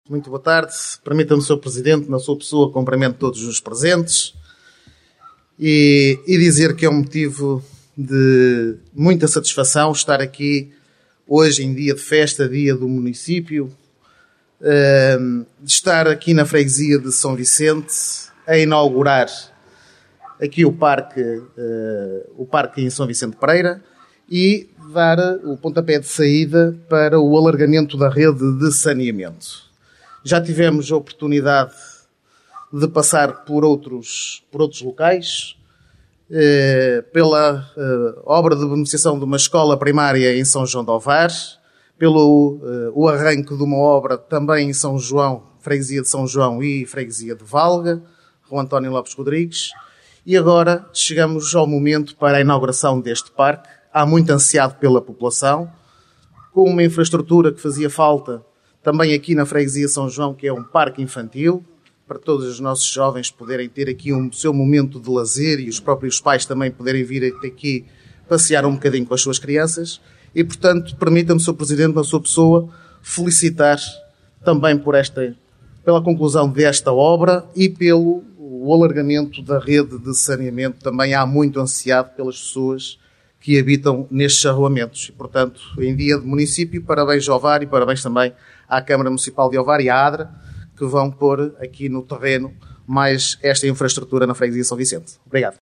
O último ato público decorreu em S. Vicente de Pereira Jusã, com a Inauguração do Parque de Lazer e Bem-Estar e com a Apresentação do Projeto “Águas Residuais do Sistema de S. Vicente de Pereira (PAR – 100) – 2ª Fase A – Ovar”, uma obra da Águas da Região de Aveiro (AdRA).
Seguiu-se a intervenção do presidente da União das Freguesias de Ovar, Bruno Oliveira, que se mostrou satisfeito com o resultado da nova área infantil e de lazer há muito desejada pelos locais.
Bruno Oliveira | União das Freguesias de Ovar